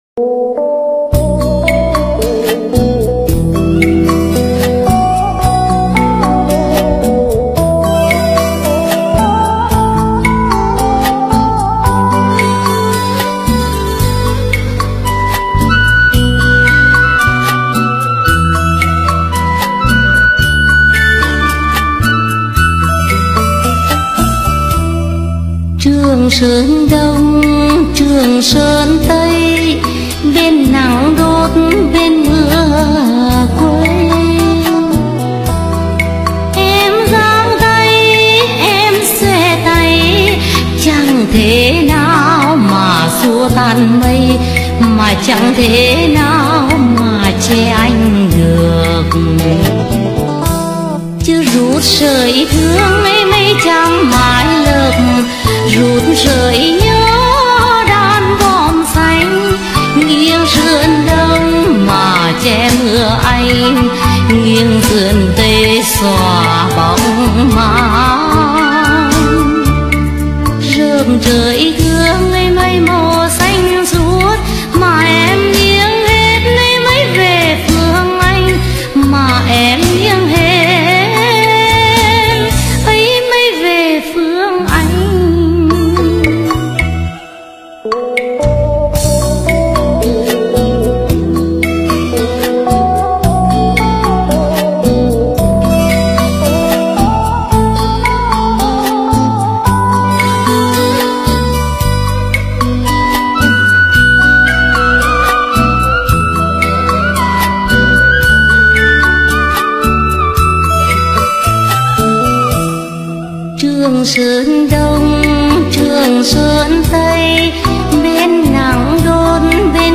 Nhạc Bolero Trữ Tình